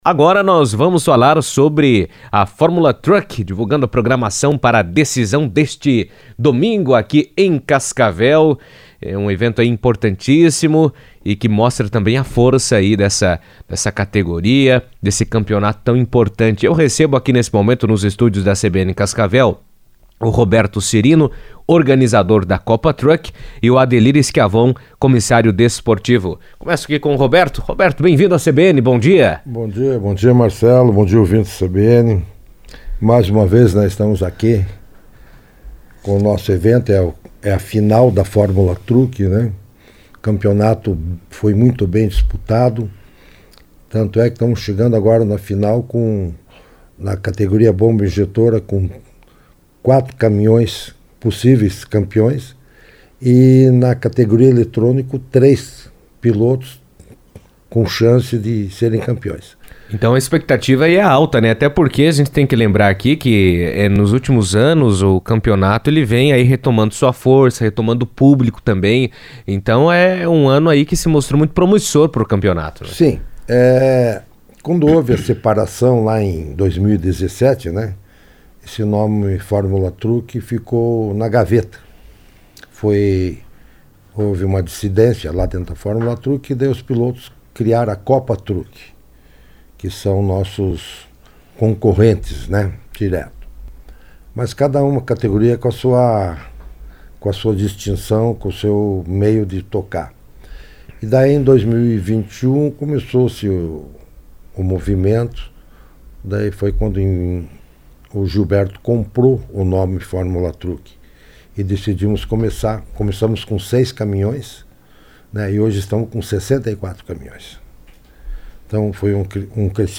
A temporada 2025 da Fórmula Truck chega ao fim neste domingo em Cascavel, com a etapa final que definirá os campeões da temporada. Em entrevista à CBN